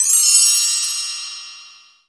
BELL TREE.WAV